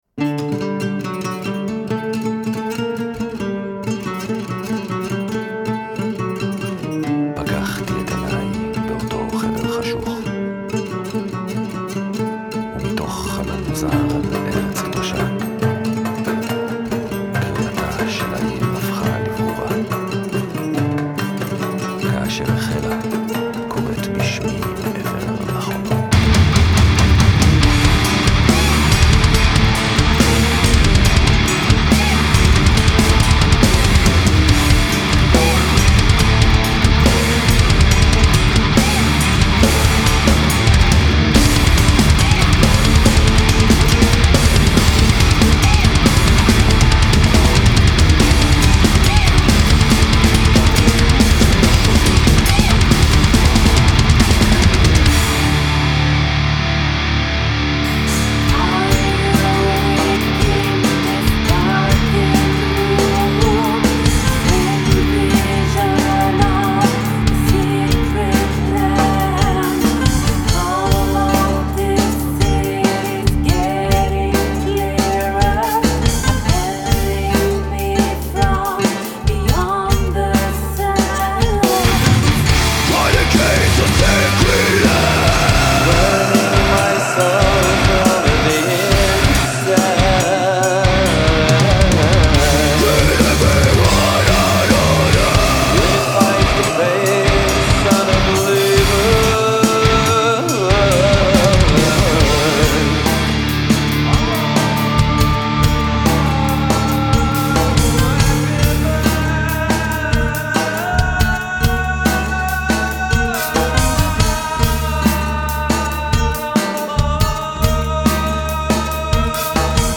Фолк Рок Арабская музыка